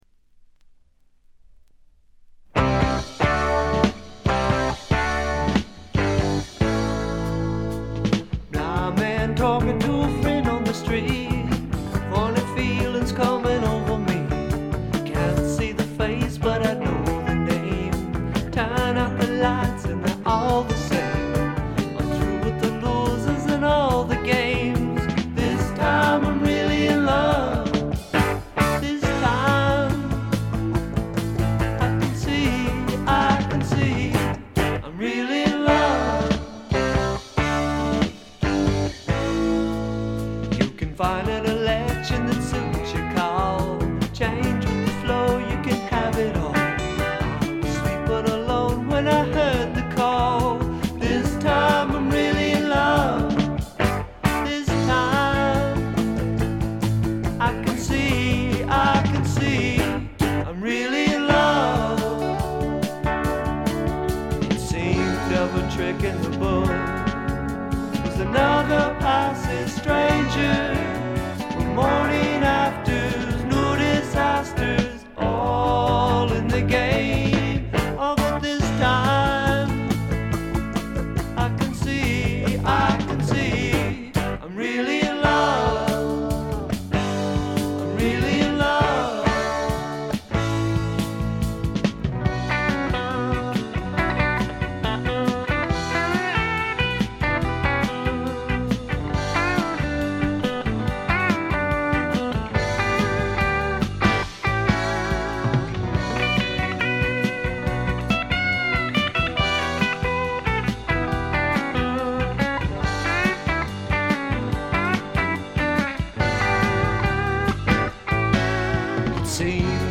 ごくわずかなノイズ感のみ。
シンプルなギター・ポップと言ったおもむきでばっちりハマる人もいそうなサウンドです。
試聴曲は現品からの取り込み音源です。
Lead Guitar, Rhythm Guitar, Vocals
Bass, Vocals
Drums